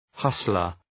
{‘hʌslər}